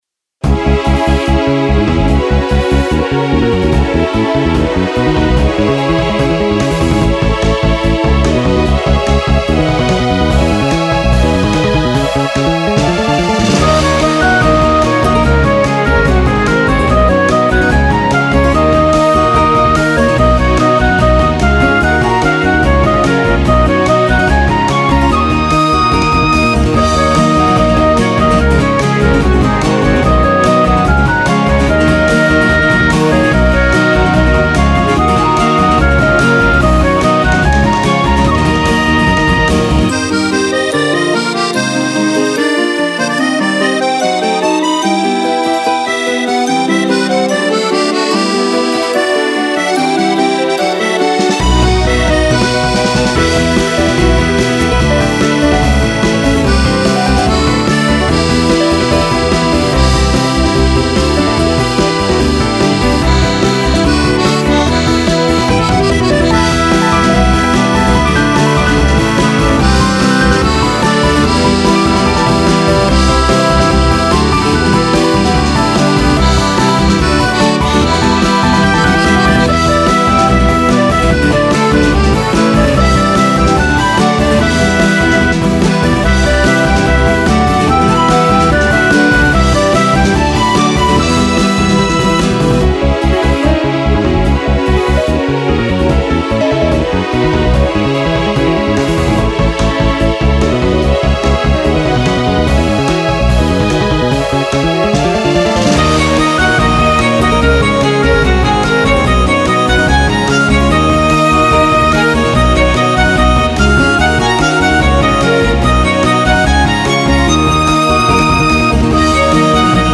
弾ける爽やかさ！狂気の打ち込み系インスト！
原曲の雰囲気を重視しながら、さらにノリ良く、激しく、狂おしいアレンジを目指しました。